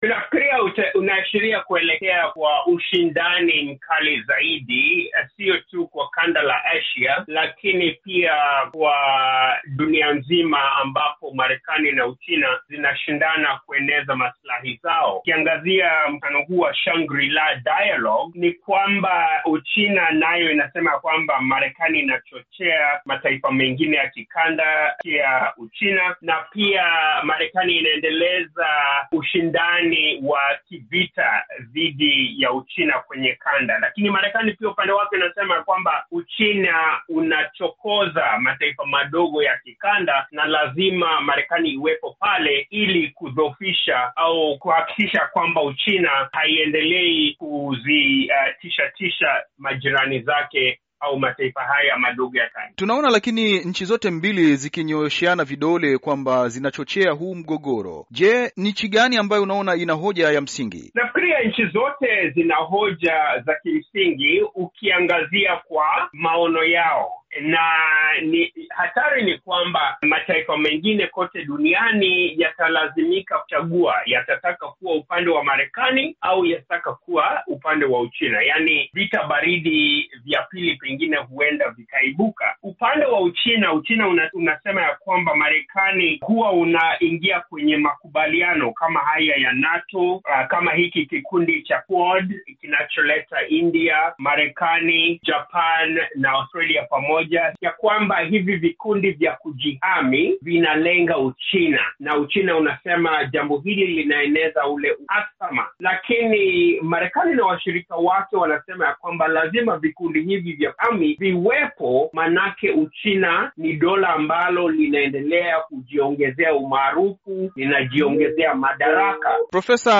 Mazungumzo